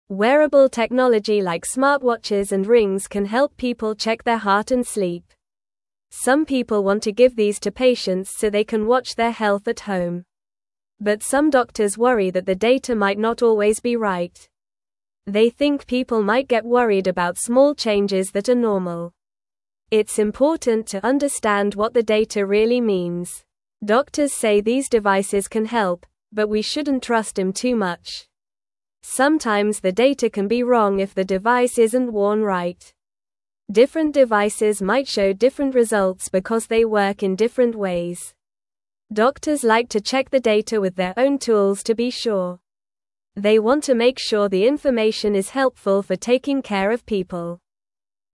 Normal
English-Newsroom-Beginner-NORMAL-Reading-Smart-Devices-Help-Check-Health-at-Home.mp3